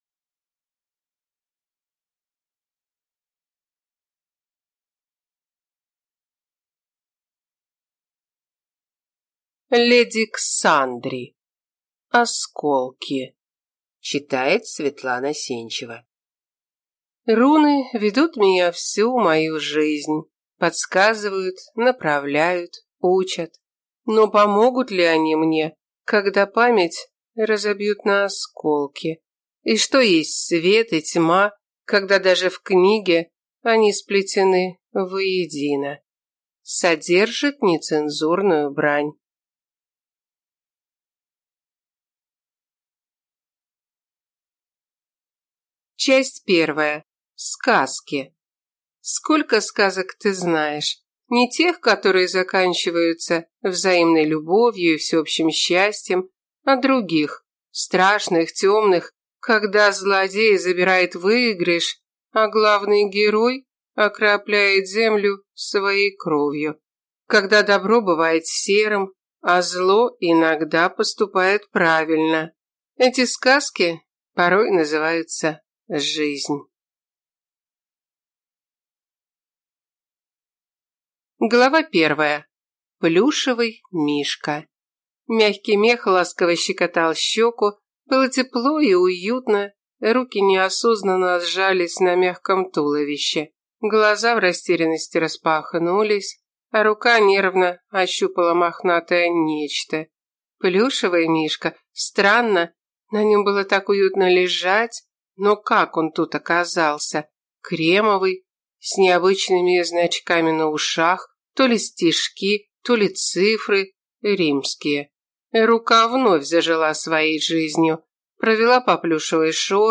Аудиокнига Осколки | Библиотека аудиокниг